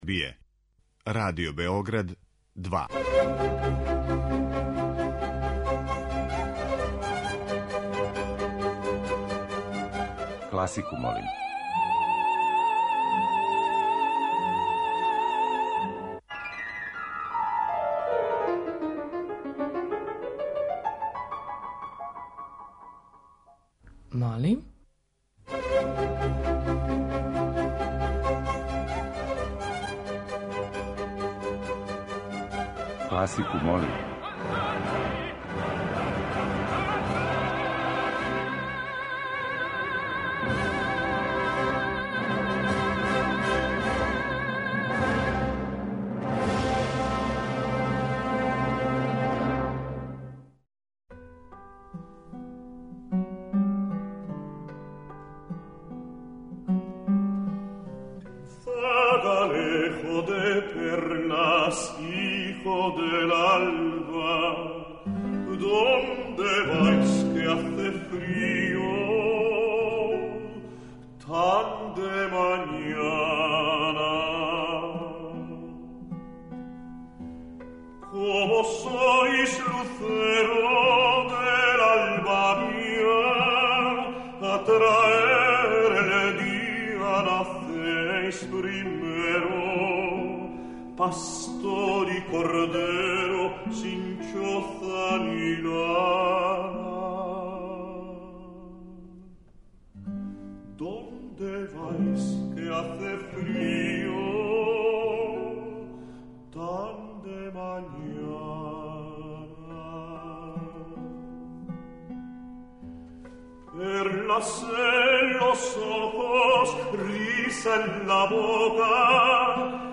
Уживо вођена емисија, окренута широком кругу љубитеља музике, разноврсног је садржаја, који се огледа у подједнакој заступљености свих музичких стилова, епоха и жанрова. Уредници (истовремено и водитељи) смењују се на недељу дана и од понедељка до четвртка слушаоцима представљају свој избор краћих композиција за које може да се гласа телефоном, поруком, имејлом или у ФБ групи.